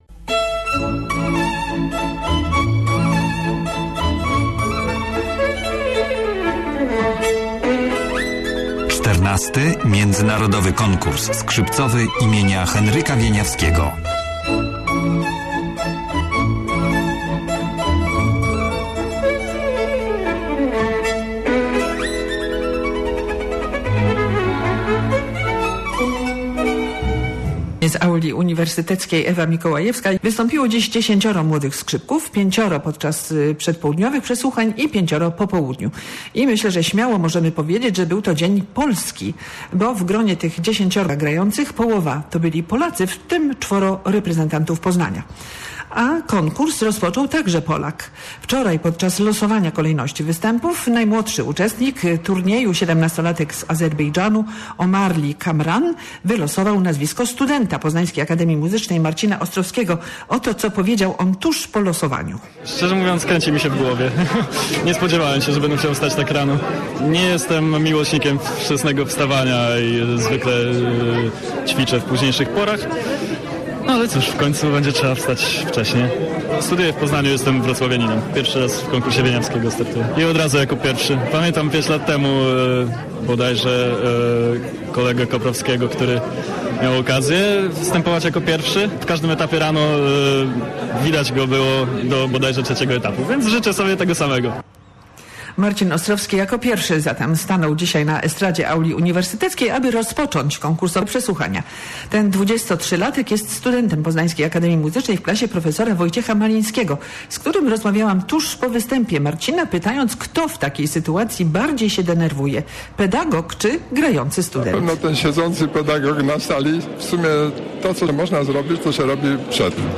W pierwszym dniu pierwszego etapu przesłuchań XIV Międzynarodowego Konkursu Skrzypcowego im. H.Wieniawskiego zagrało 10 uczestników.